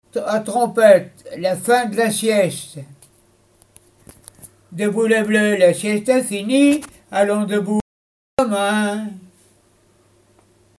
chansons traditionnelles et d'école
Pièce musicale inédite